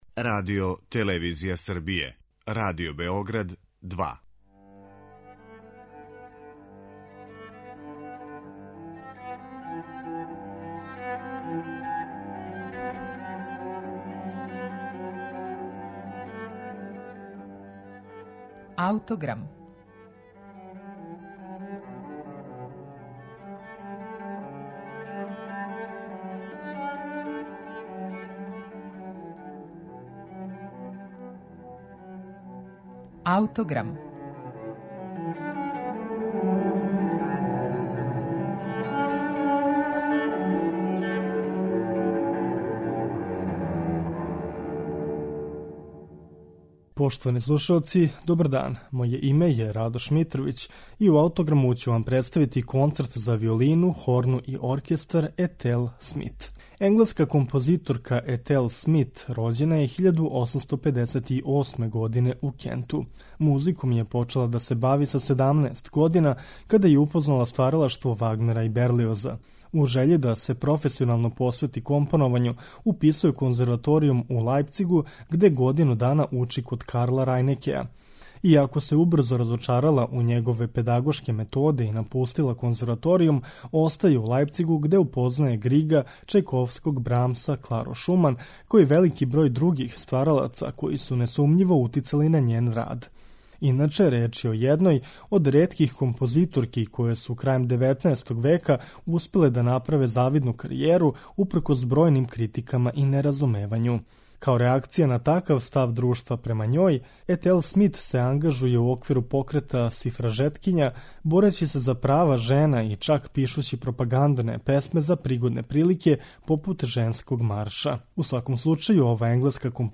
Концерт за виолину, хорну и оркестар, Етел Смит
У Аутограму представљамо Концерт за виолину, хорну и оркестар, који показује њен стилски израз и луцидан третман солистичких партова.